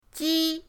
ji1.mp3